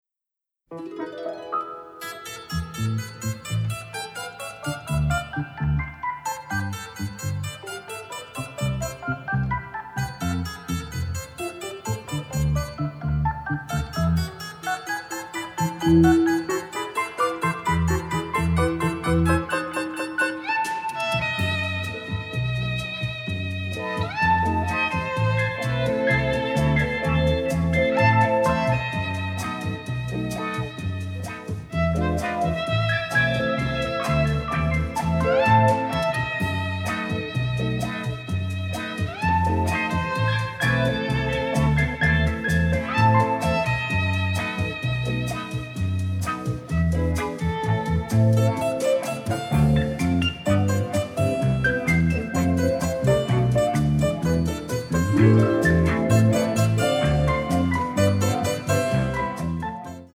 great exotica and pop tunes